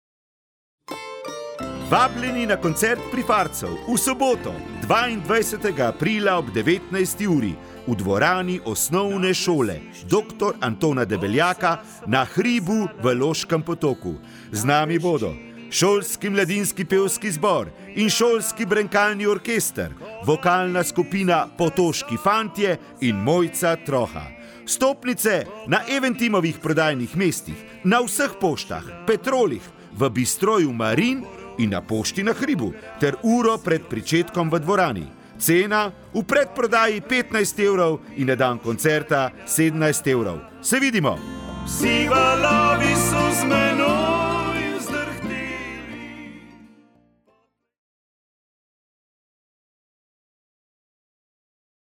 Napovednik - Prifarci (2,1MB)